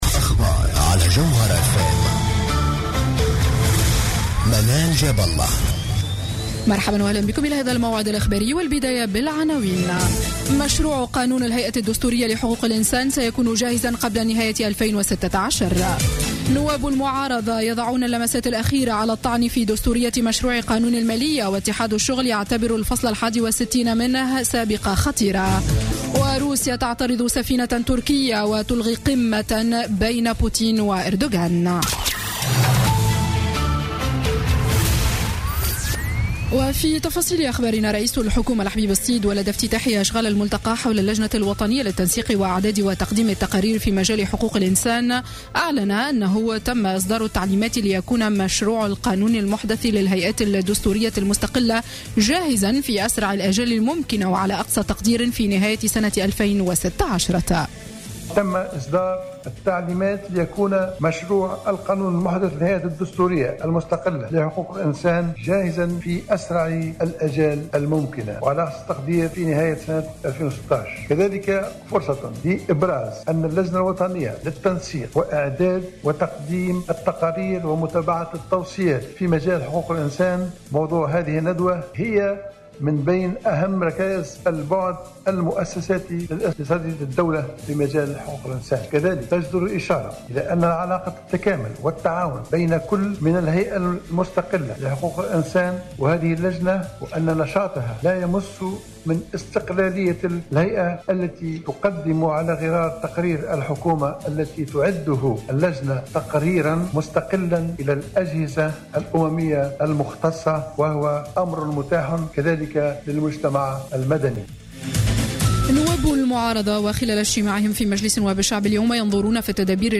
نشرة أخبار السابعة مساء ليوم الاثنين 14 ديسمبر 2015